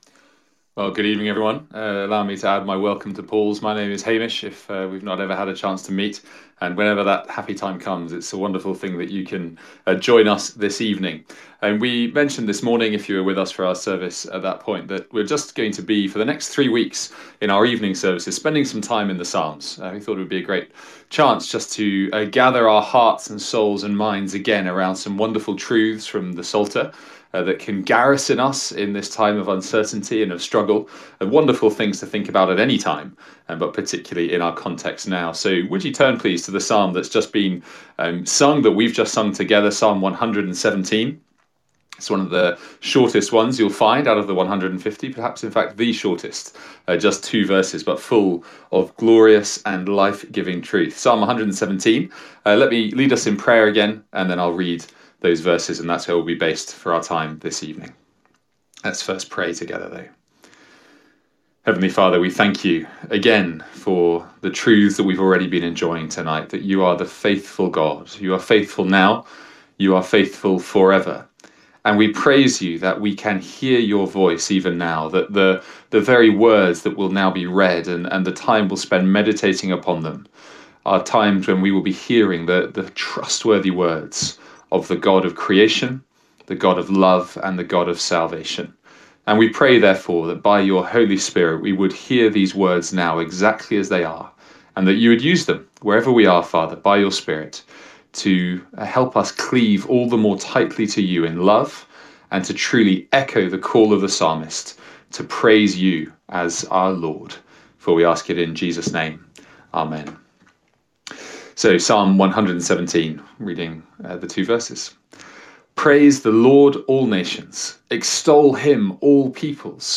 A one-off Sunday morning sermon on Psalm 117.